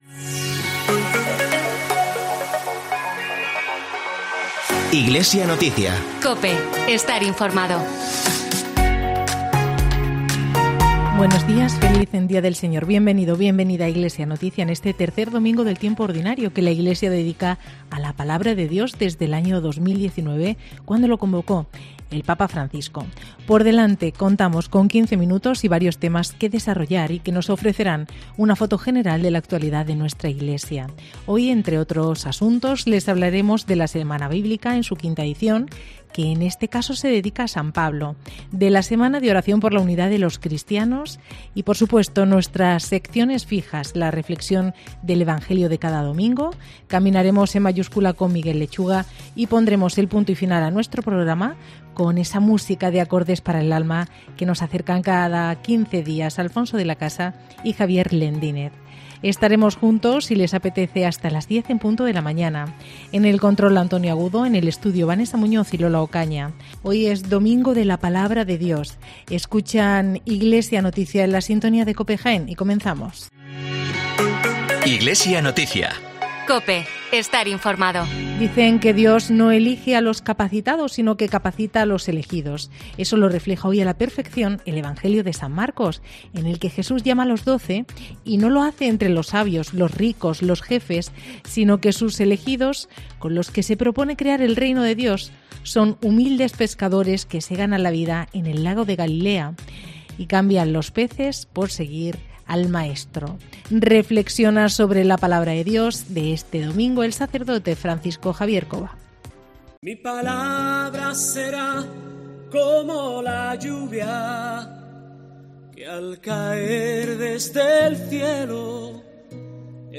informativo Iglesia Noticia